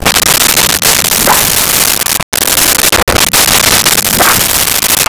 Cougar Snarl Growl 01
Cougar Snarl Growl 01.wav